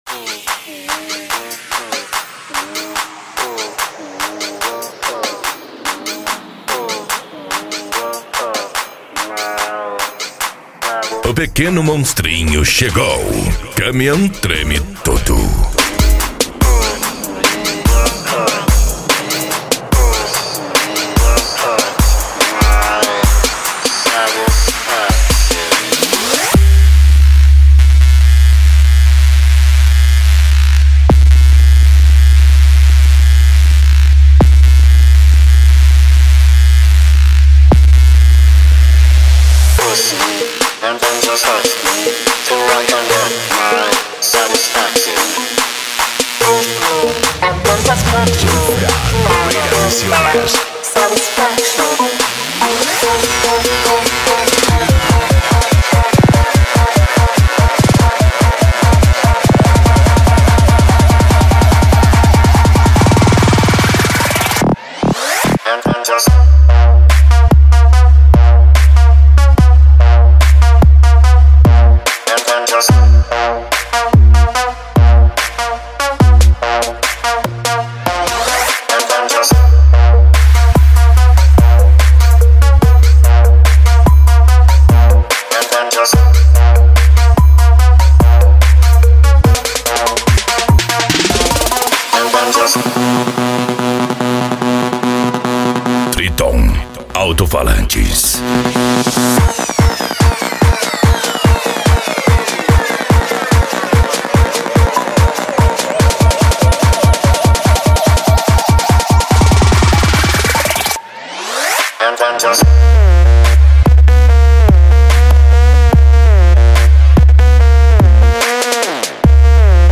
Modao
PANCADÃO
Remix